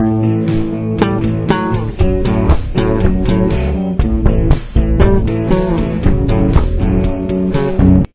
Verse
The first guitar you see is still in standard.
Here it is with the bass :